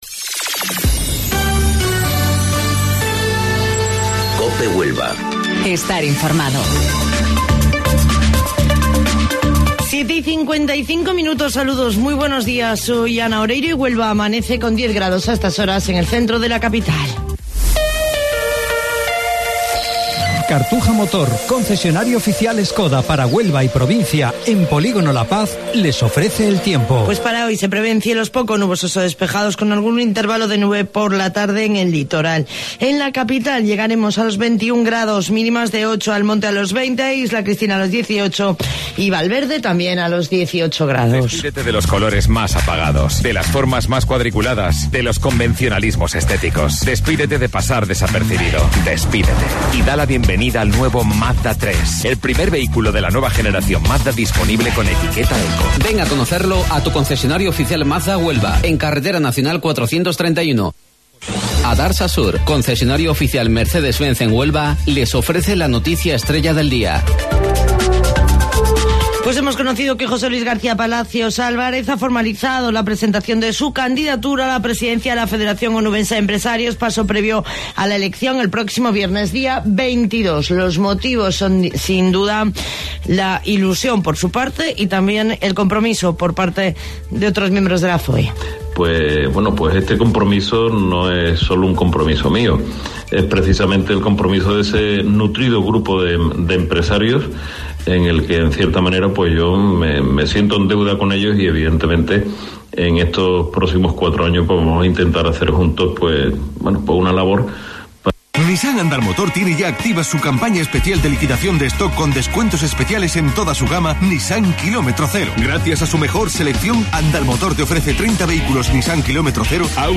AUDIO: Informativo Local 07:55 del 20 de Marzo